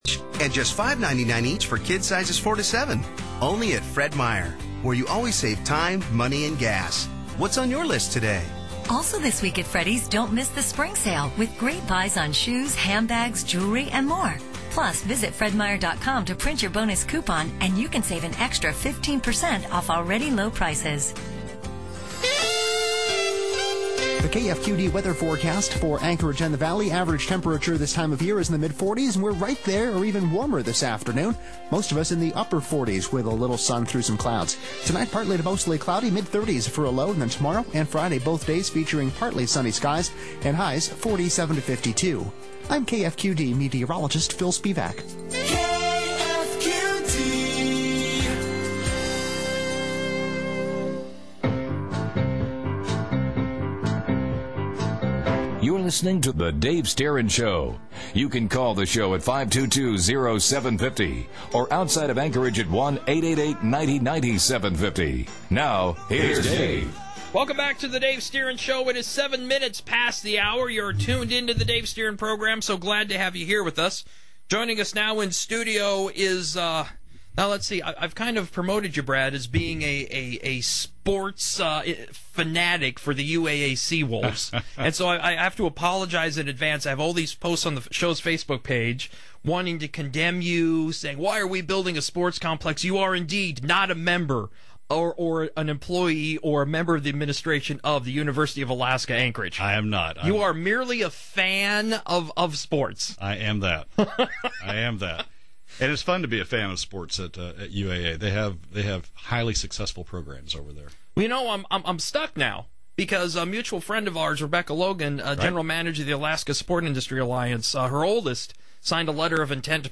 In the course of addressing the issues which are on the agenda for the Special Session, we also touched on the University of Alaska Anchorage Volleyball Team, Dick Clark’s legacy and started toward — but didn’t quite reach, yet — bluegrass music. The discussion is at the first half of the third hour yesterday.